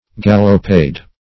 Gallopade \Gal"lo*pade`\, n. [F. galopade. See Gallop, n.]